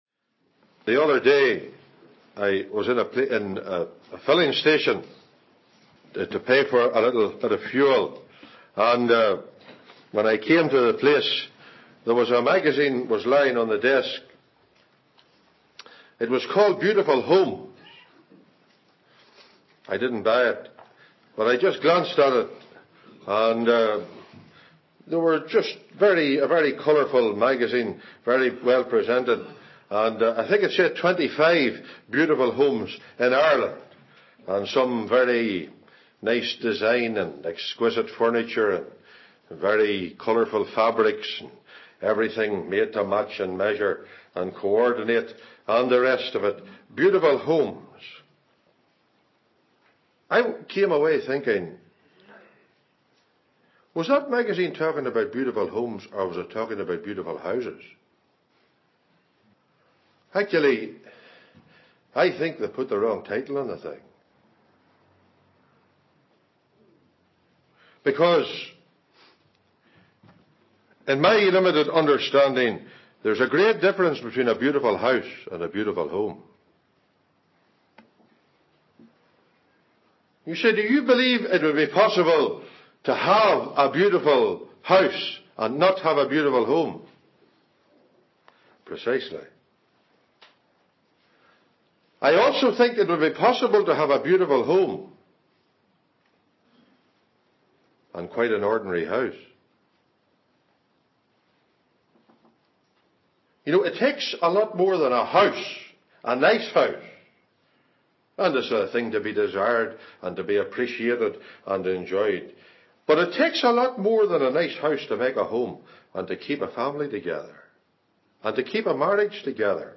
He looks at the family in their home, the features of the home, the focus of the home, the fugitive from the home (Onesimus) etc. Readings: Philemon, Col 4:15-18. (Message preached in Northern Ireland)